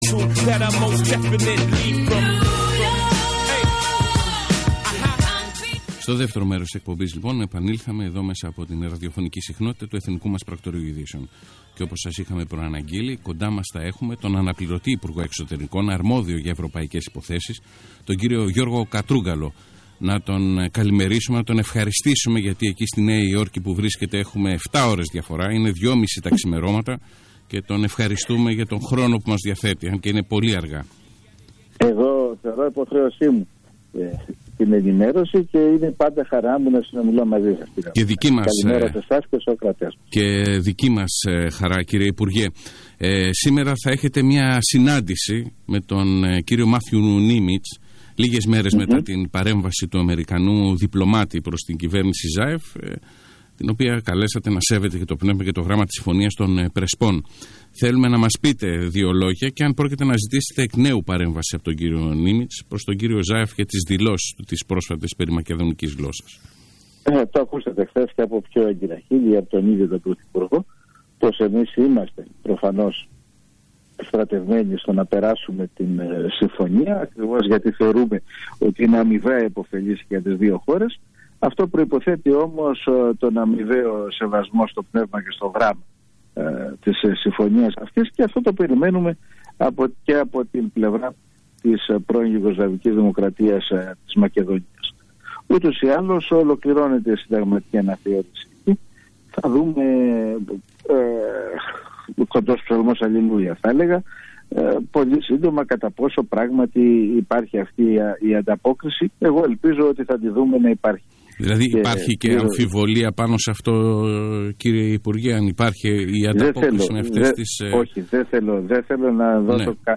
Συνέντευξη του Αν. Υπουργού Εξωτερικών, Γ. Κατρούγκαλου, στο ρ/δ σταθμό «Πρακτορείο 104,9» - Hellenic Republic - Ministry of Foreign Affairs